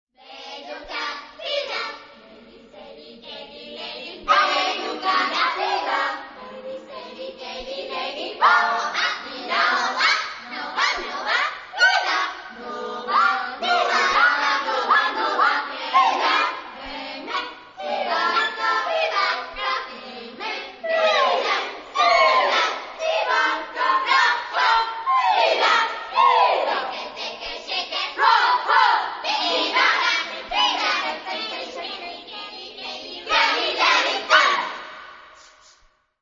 Genre-Style-Form: Secular
Type of Choir: distribution non spécifiée children OR women
Tonality: atonal